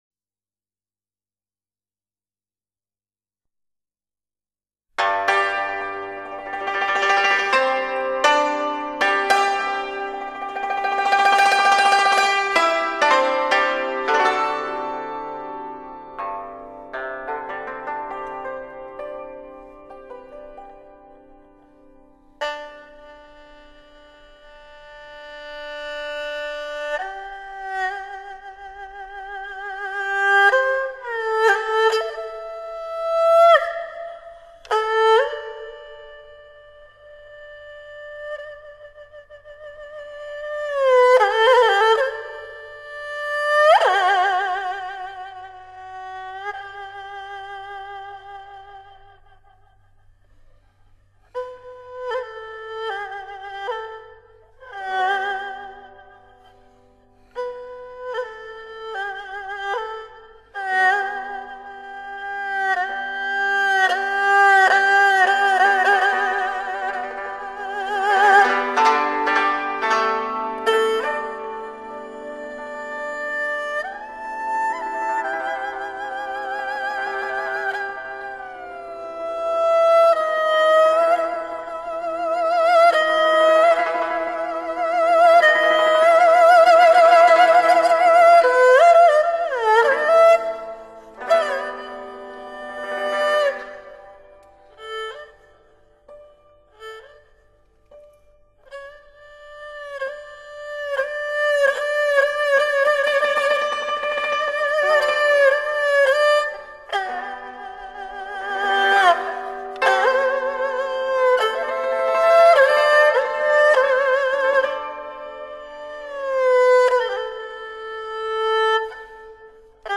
山东民间乐曲